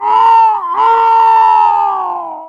TF2 Pyro Death Scream
team-fortress-2-pyro-death-scream.mp3